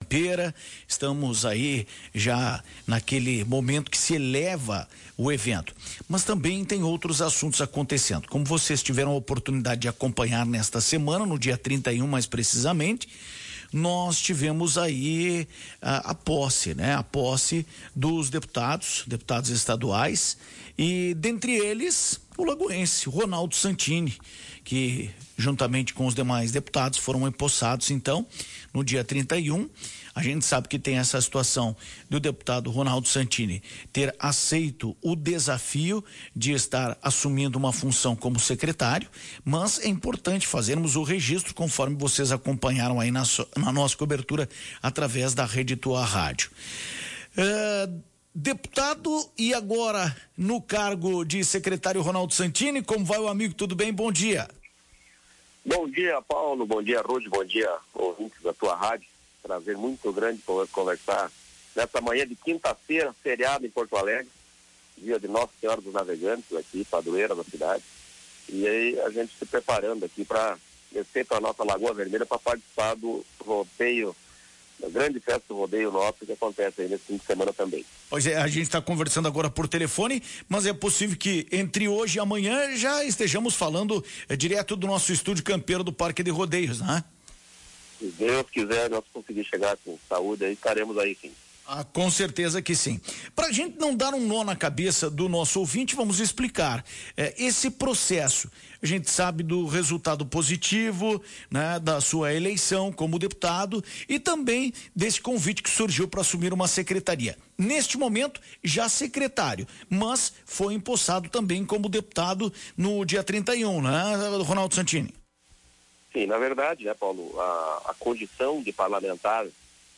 Em entrevista à Tua Rádio Cacique, Santini falou sobre o movimento e o trabalho junto à secretaria. O lagoense explicou as ações e parcerias realizadas pela Secretaria do Desenvolvimento Rural, e confirmou sua vinda ao Rodeio Internacional de Lagoa Vermelha.